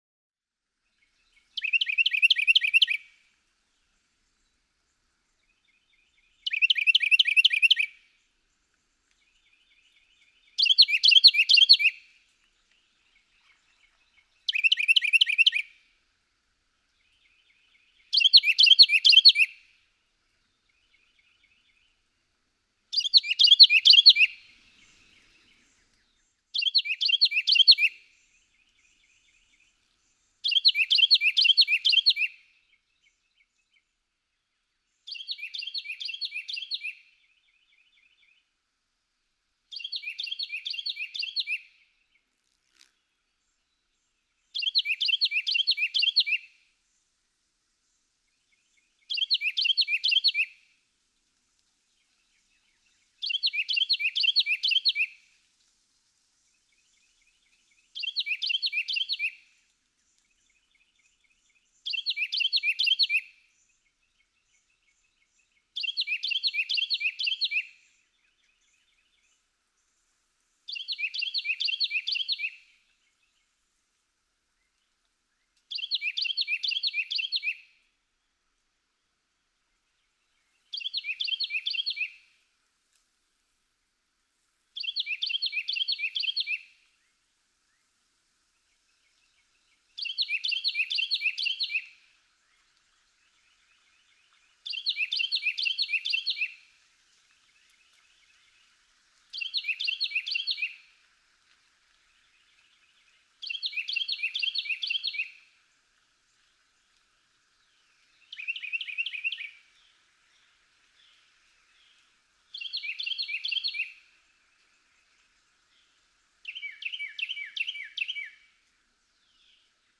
Carolina wren
♫167. Adult male song, a more inspired performance; a second song type is introduced at 0:10, a third at 1:56, a fourth at 2:48. After 3:42, he often alternates two different songs.
Yorktown National Battlefield, Yorktown, Virginia.
167_Carolina_Wren.mp3